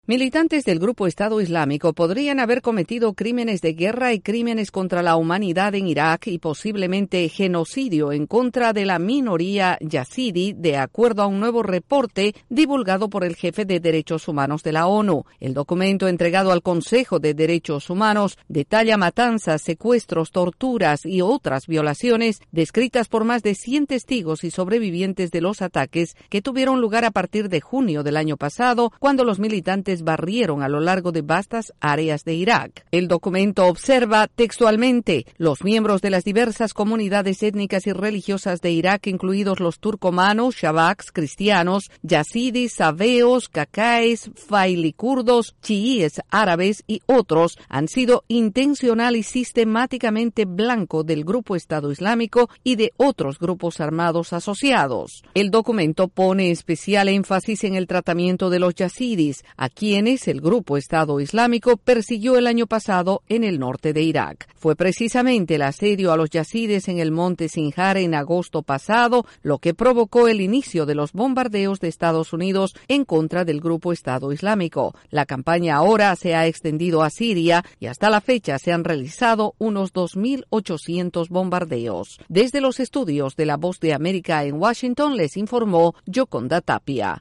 La ONU dice que el grupo Estado islámico podría haber cometido crímenes de guerra y contra la humanidad. El informe desde la Voz de América en Washington DC